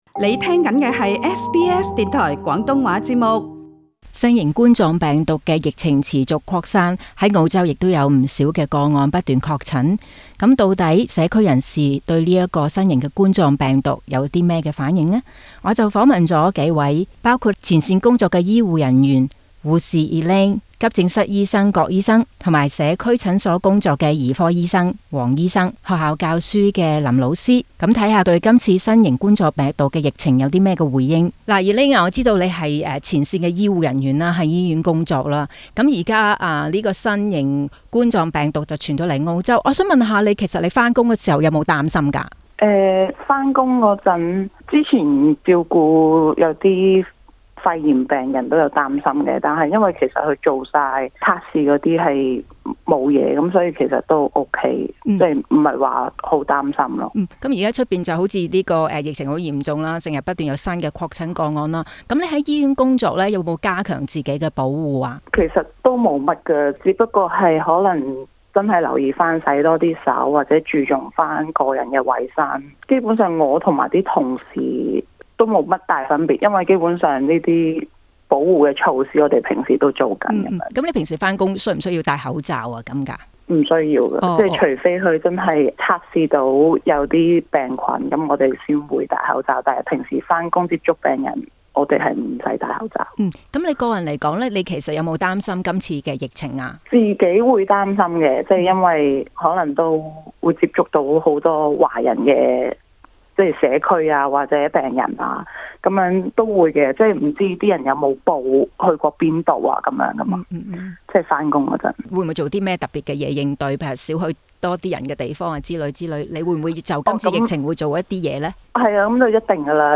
醫護人員和教師接受本台訪問時都強調，態度要謹慎，保持個人衛生，卻無需恐慌，四處搜羅口罩亦無必要。